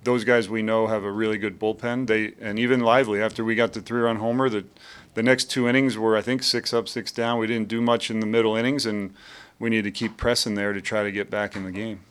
KC Royals manager Matt Quatraro on the struggle to score against the Guardians reliefers after starter Ben Lively.